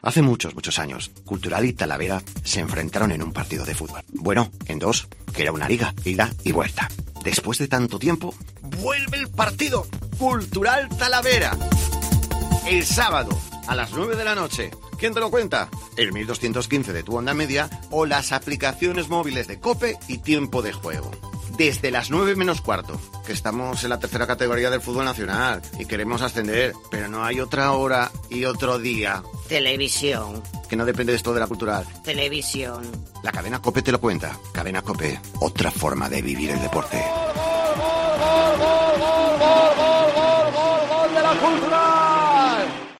Escucha la cuña promocional del partido Cultural - Talavera el día 09-10-21 a las 21:00 h en el 1.215 OM